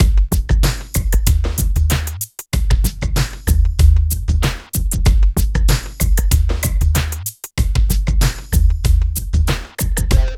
62 DRUM LP-L.wav